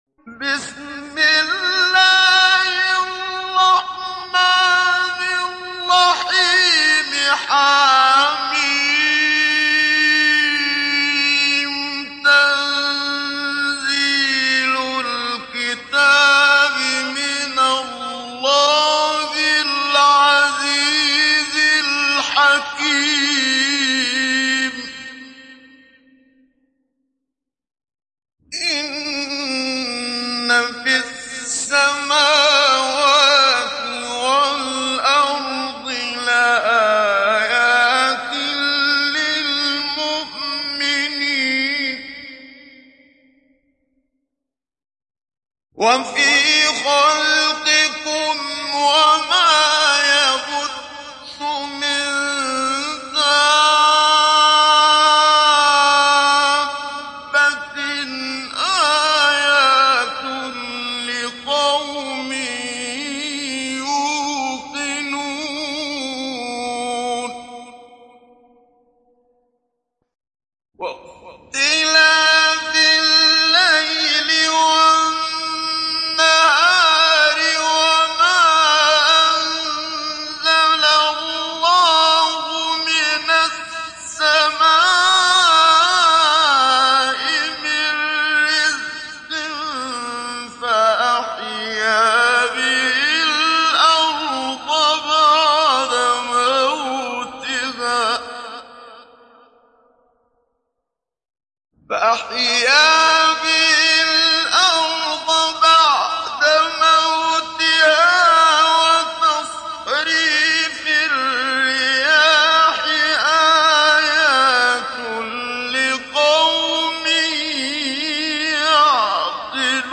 Télécharger Sourate Al Jathiyah Muhammad Siddiq Minshawi Mujawwad